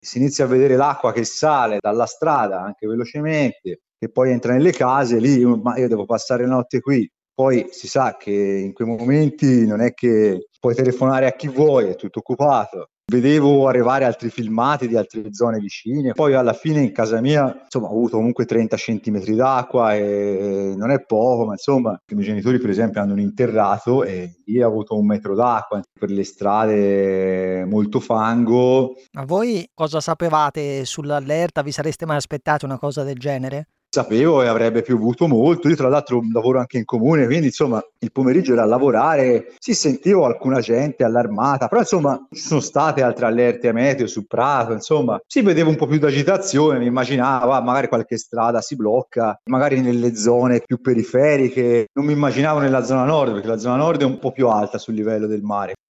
Gli abbiamo chiesto di raccontarci la situazione e cosa è successo a partire da ieri sera.